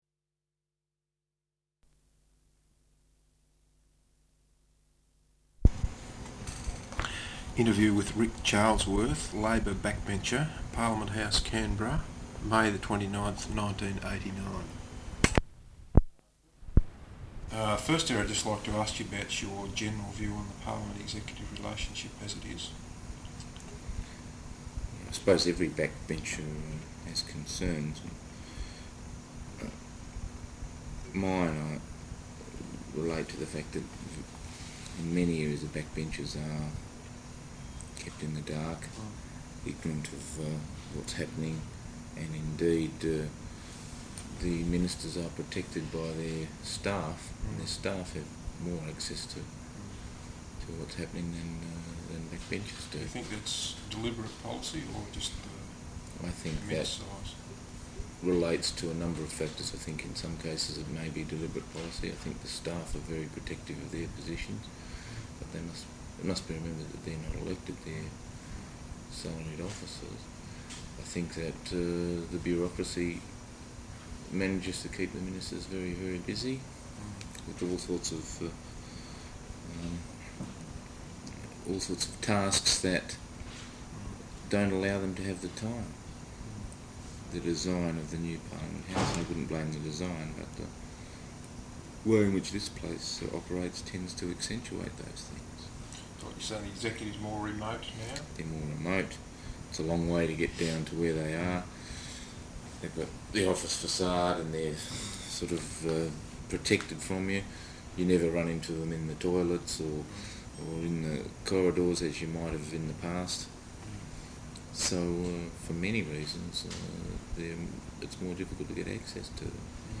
Interview with Ric Charlesworth, Labor Backbencher, Parliament House, Canberra May 29th, 1989.